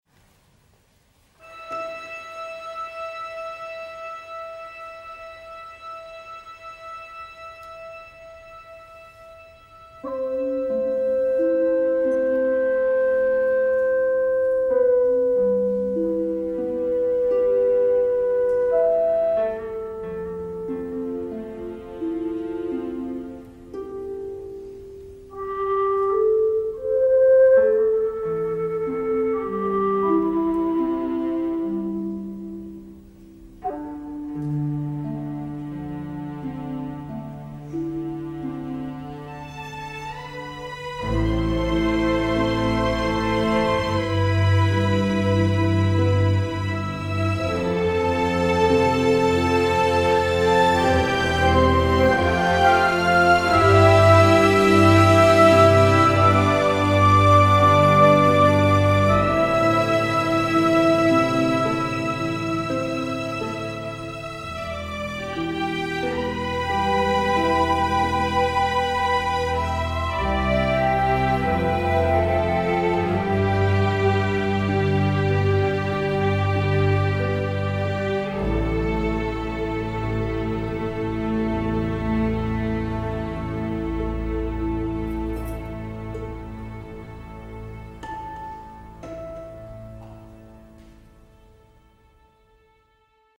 Я взял из него мидишки и прямо "как есть" перекинул в сегодняшний темплейт. Ничего не крутил заново, только прописал модуляцию, которой в "эдироле" не было, а если и была, то я о ней не подозревал. Делал быстро, даже баланс особо не трогал.